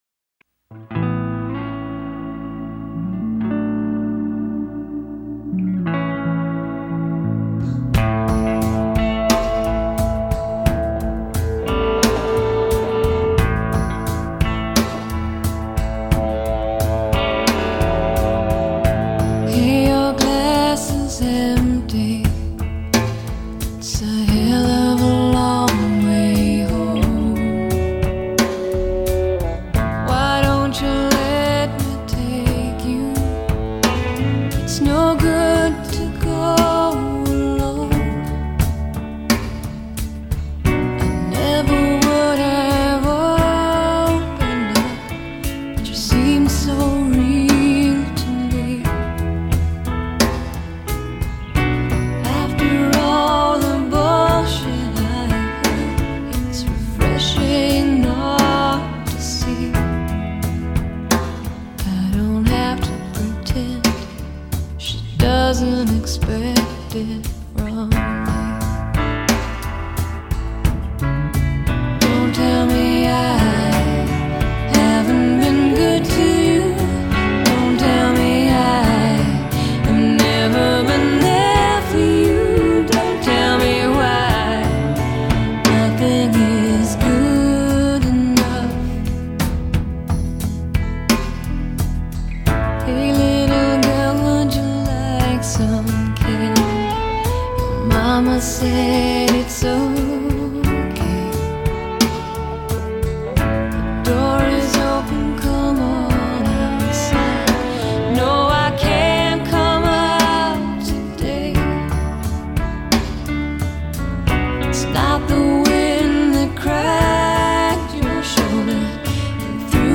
Canadian singer-songwriter
sadly sexy